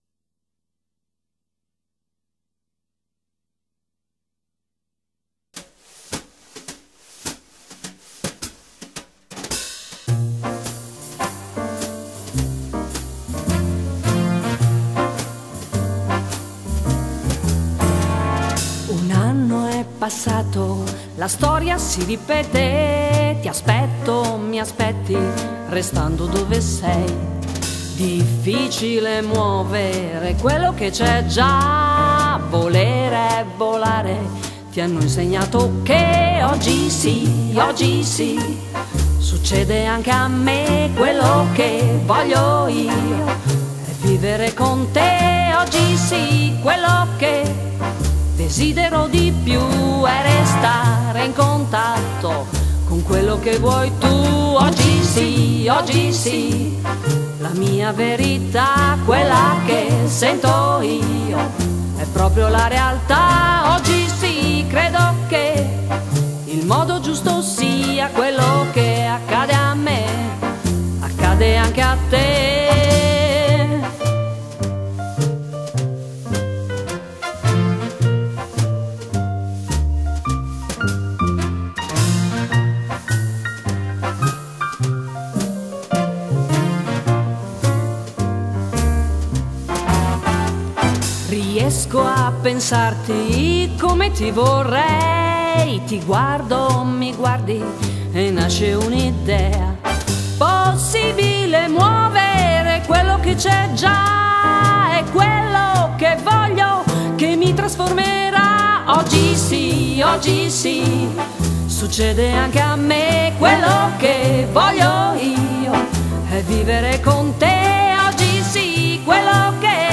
Luogo esecuzionebologna
GenerePop